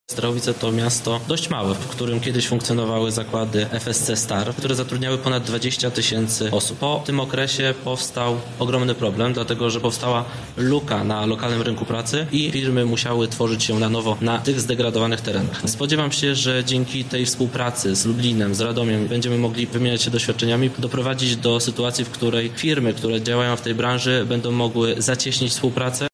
O roli Starachowic w tym porozumieniu mówi prezydent miasta, Marek Materek